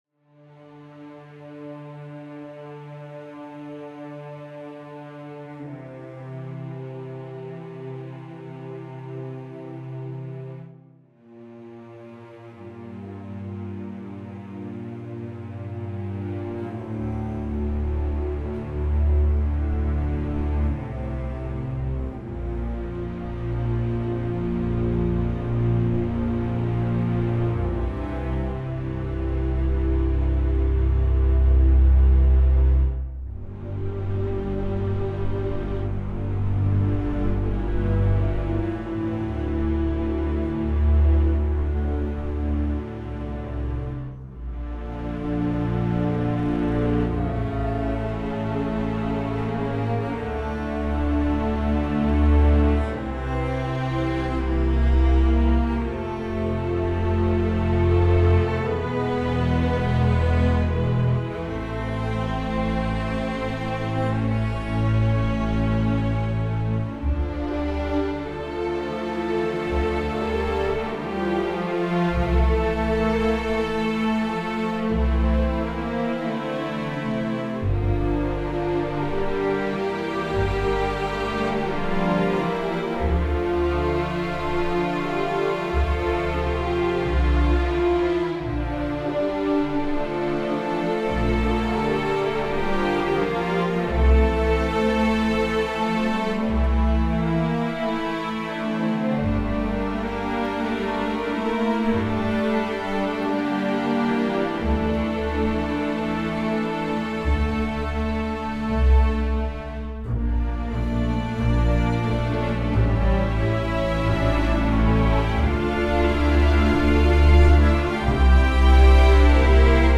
orchestral strings library
The result is a string library that is easy to use, composer-friendly, with unparalleled recording quality.